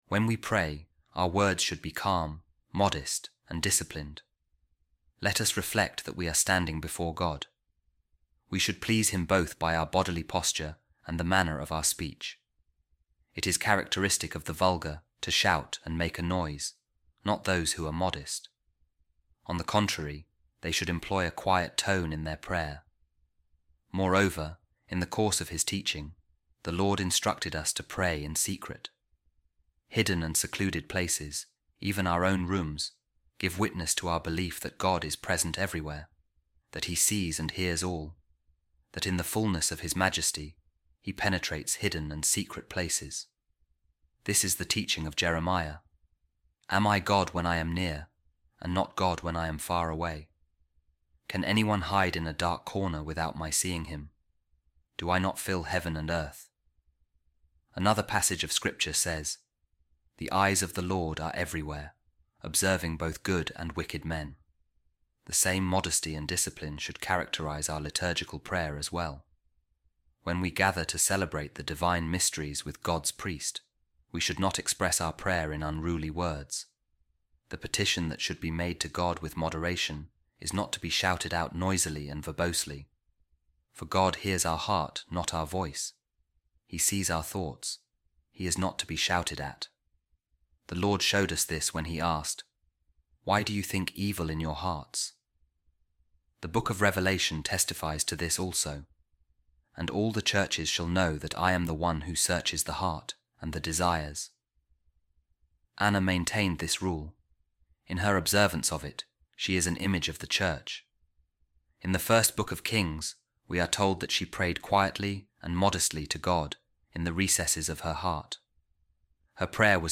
Office Of Readings | Week 11, Sunday, Ordinary Time | A Reading From The Treatise Of Saint Cyprian On The Lord’s Prayer | Let Prayer Be Made By A Humble Heart